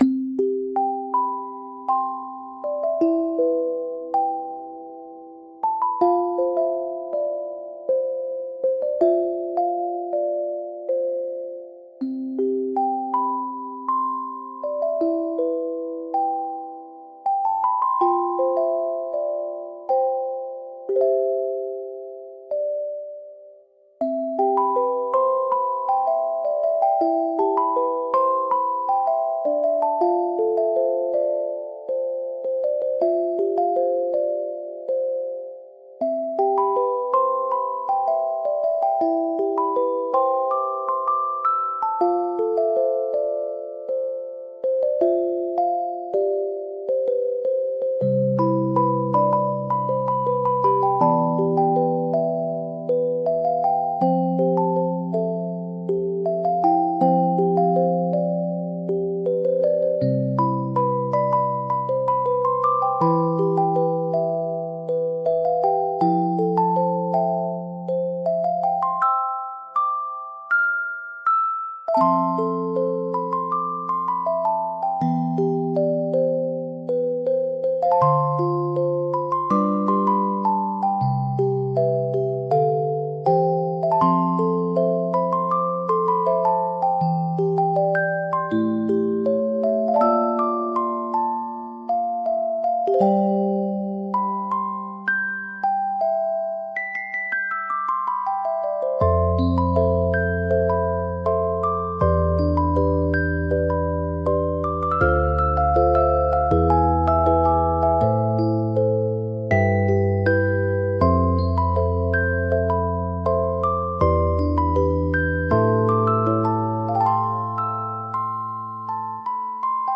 ゆっくり眠れるBGM